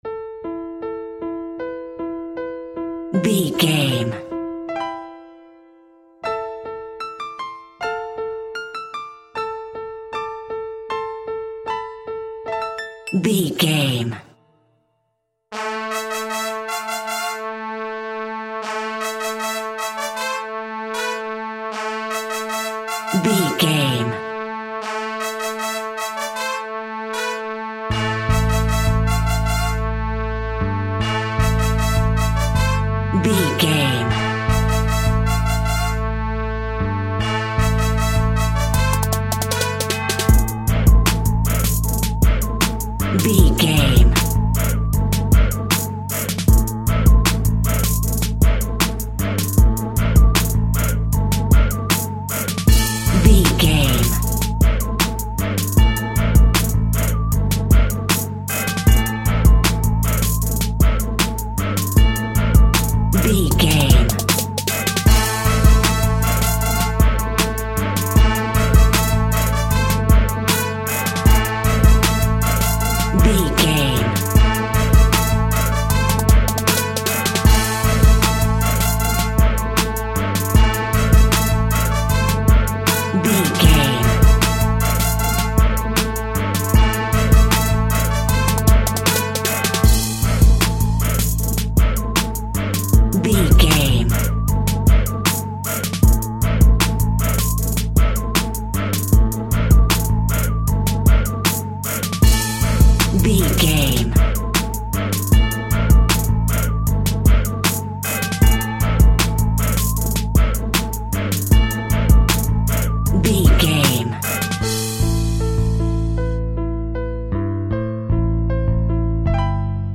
Aeolian/Minor
rap
chilled
laid back
groove
hip hop drums
hip hop synths
piano
hip hop pads